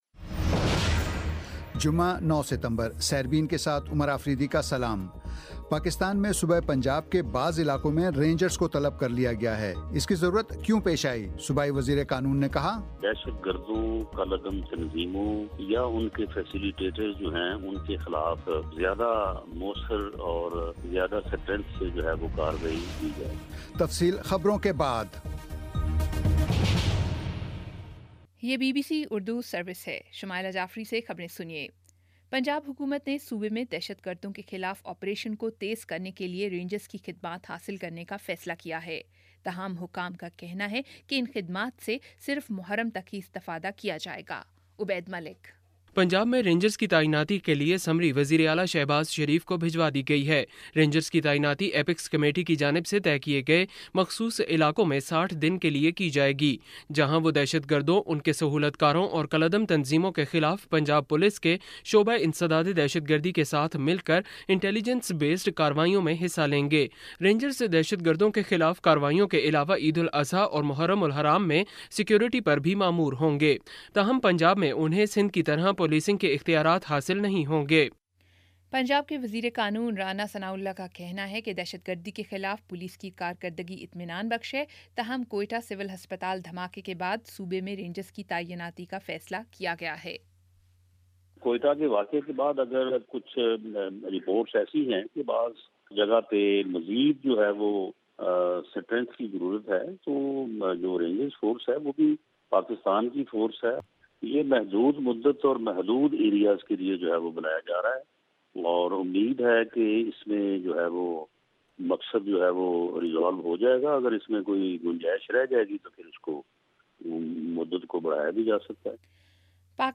جمعہ 09 ستمبر کا سیربین ریڈیو پروگرام